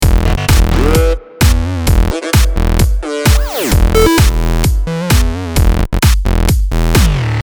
DTM講座　Electro House （エレクトロ ハウス）の作り方④
最後のすべてのMIDIデータをみてもらえば分かるように、音が重なっている部分はほんの少しです。